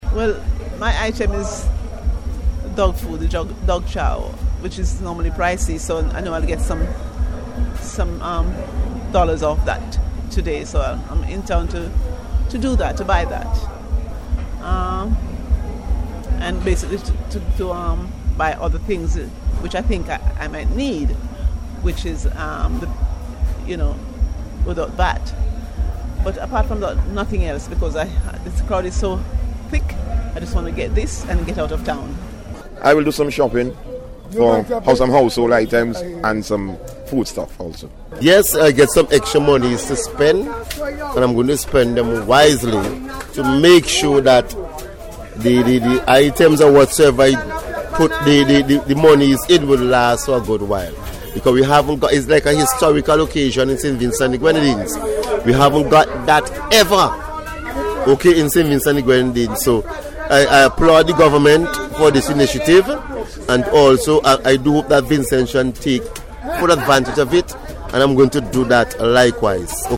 NBC News took to the streets to find out what they are purchasing